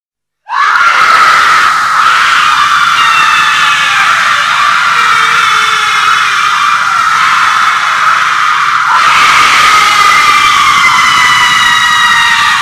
Scary_ringtone_.mp3